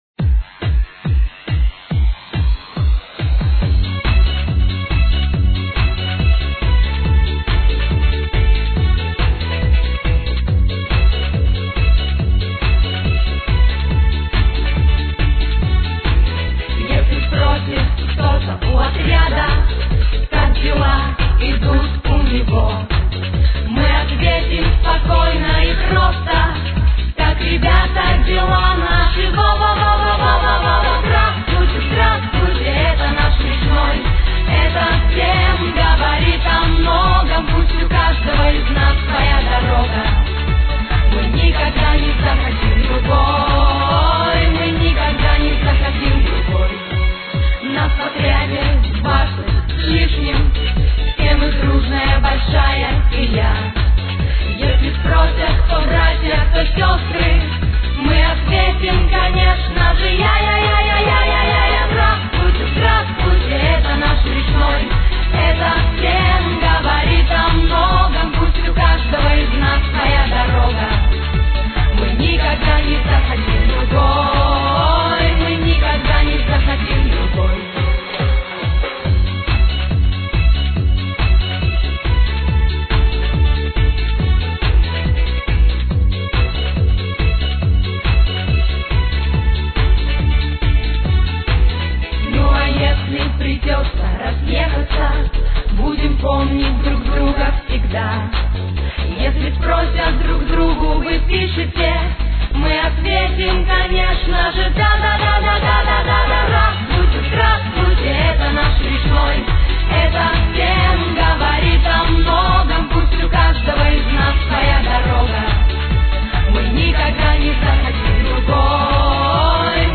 Слова фонограмма (mp3)